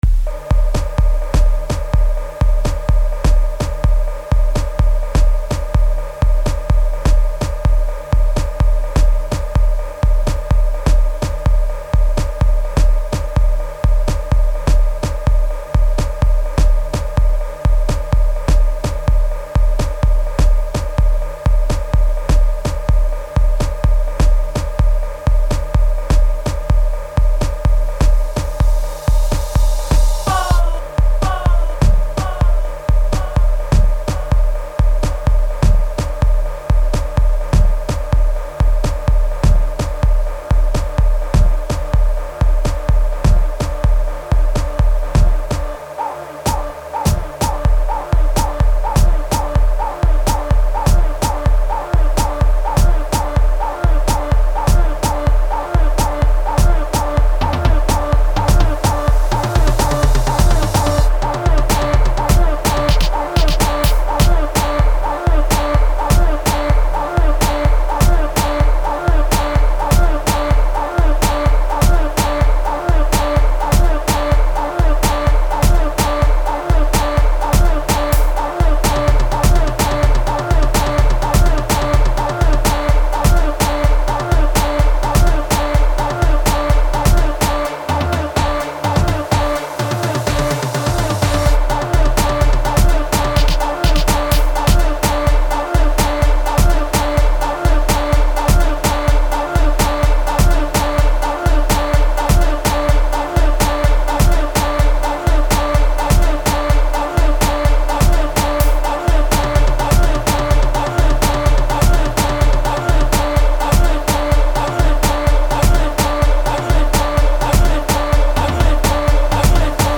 06:10 Genre : Gqom Size